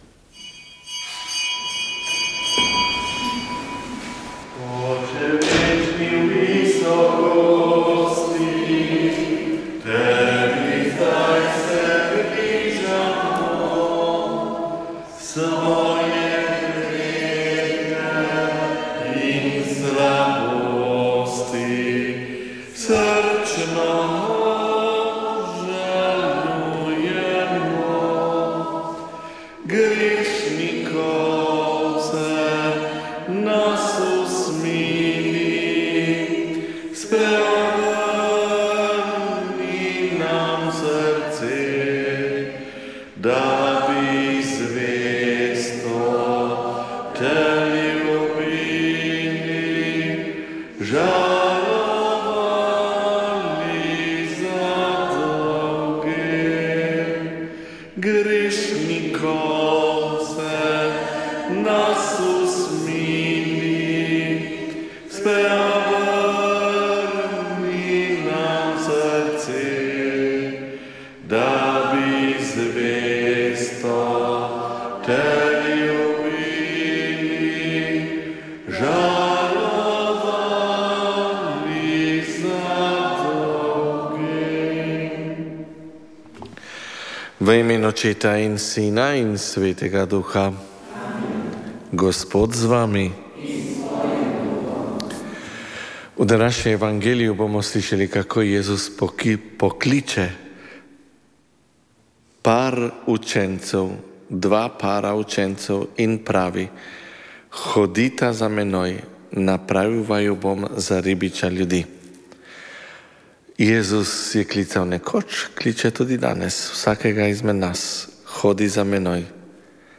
Sveta maša
Sv. maša iz cerkve sv. Marka na Markovcu v Kopru 5. 1.